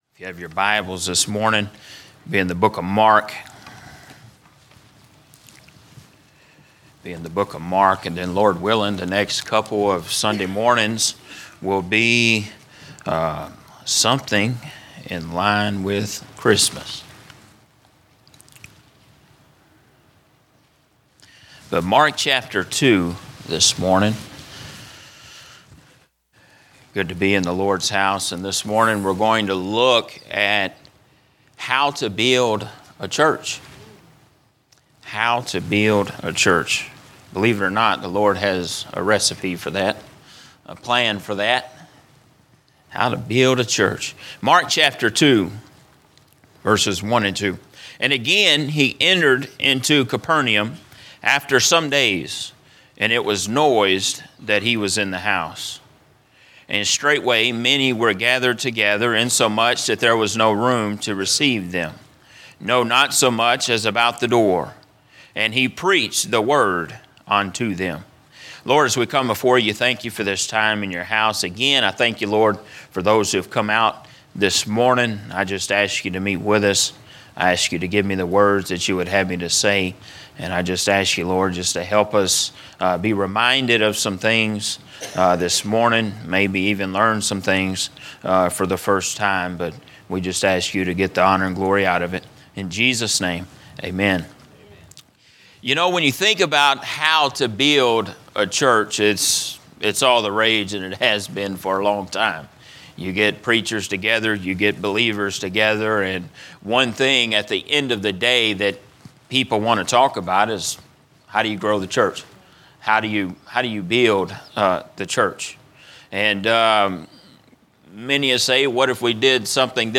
Message
A message from the series "Healthy Church Culture." A study of what healthy church culture is as laid out in 1st Thessalonians chapter 5.